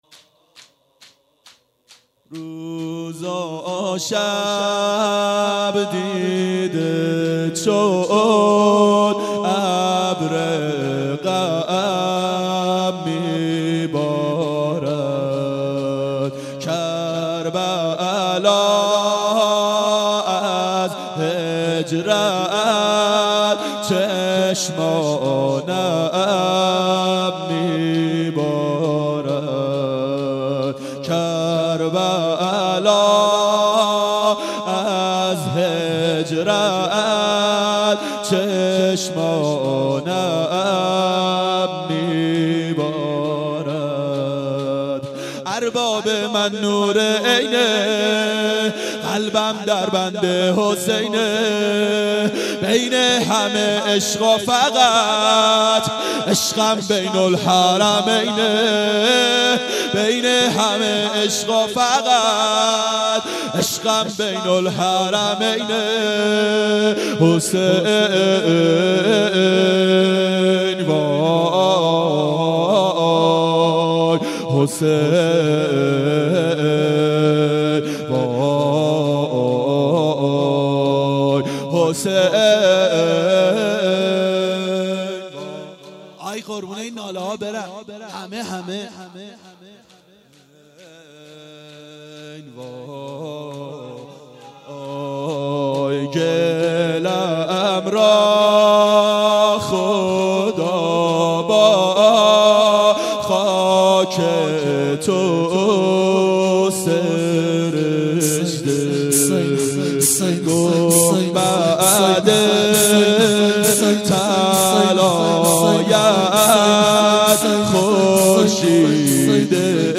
شب عاشورا 1391 هیئت عاشقان اباالفضل علیه السلام